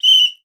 Foley Sports / Whistle / Soccer Foul.wav
Soccer Foul.wav